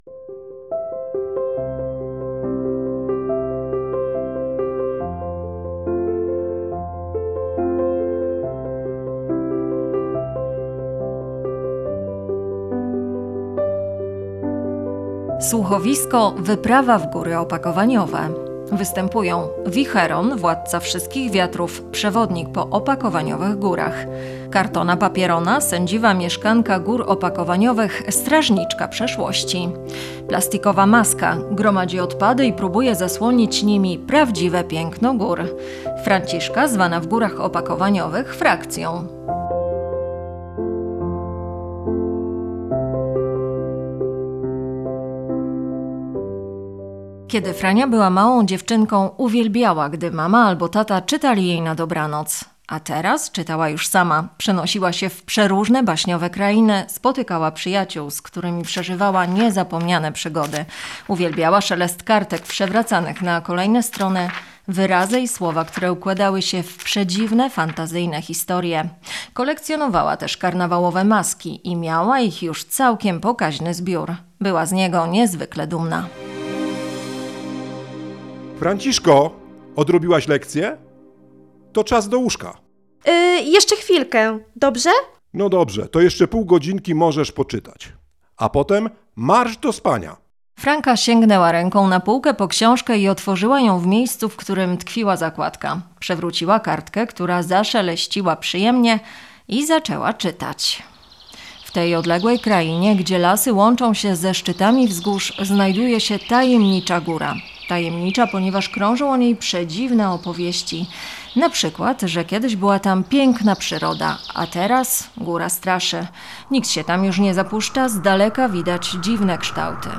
Słuchowisko "Wyprawa w Góry Opakowaniowe"
Sluchowisko-Wyprawa-w-Gory-Opakowaniowe.mp3